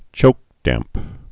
(chōkdămp)